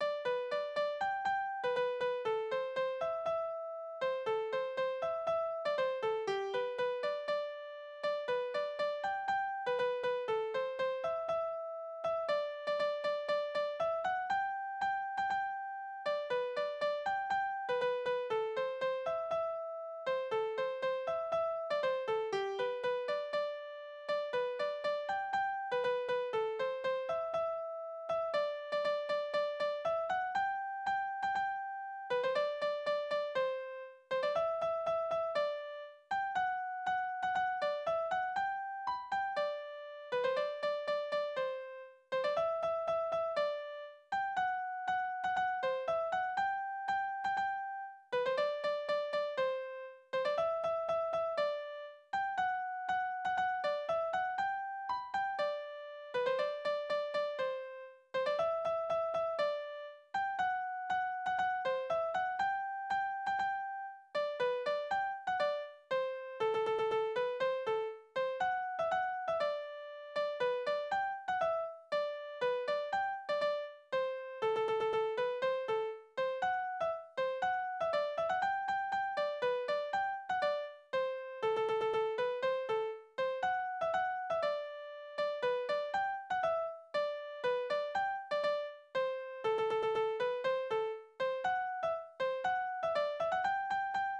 « 10883 » "Hamburger" oder "Kreuzpolka" Tanzverse: Kreuzpolka Tonart: G-Dur Taktart: 2/4 Tonumfang: Oktave Besetzung: instrumental Externe Links: Sprache: hochdeutsch eingesendet von Aug.